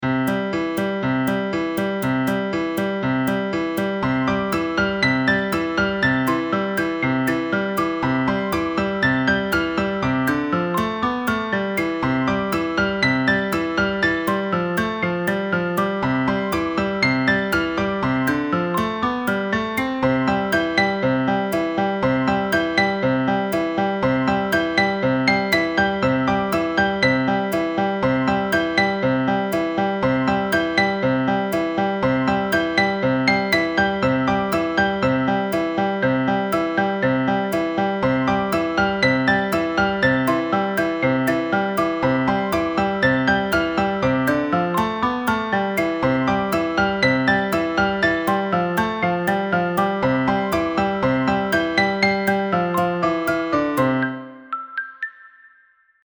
最終的に出来たBGMがこれ。
BGM作ってて何となく木琴がお気に入り。